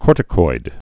(kôrtĭ-koid)